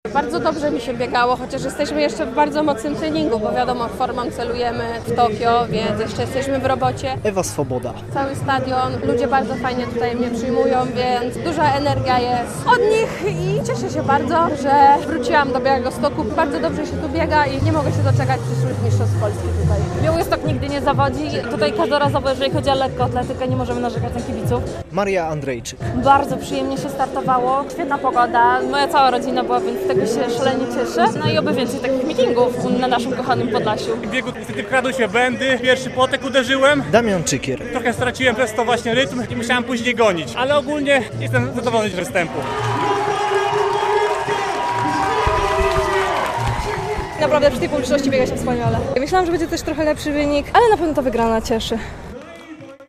Mityng Ambasadorów Białostockiego i Podlaskiego Sportu - relacja